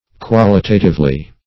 Qual"i*ta*tive*ly, adv.